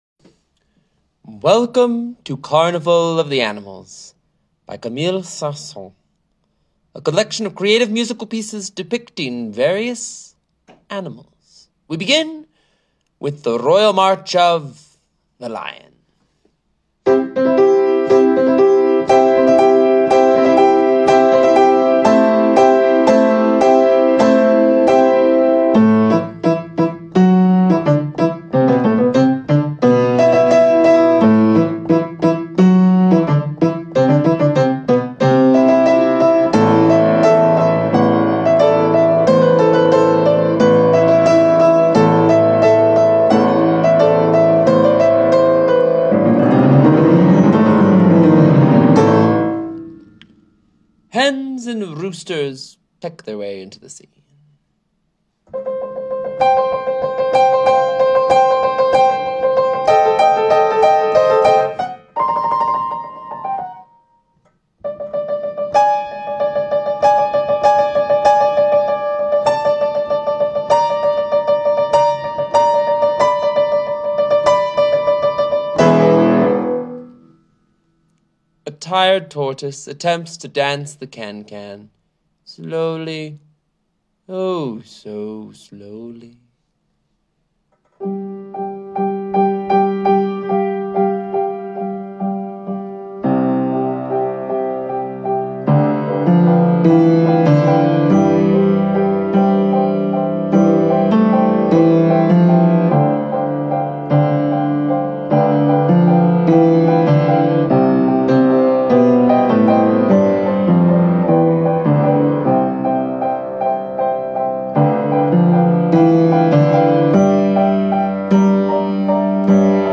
Viola and piano duet